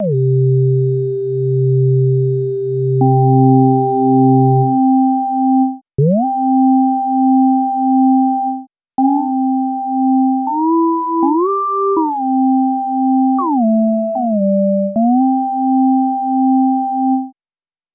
Track 16 - Synth 01.wav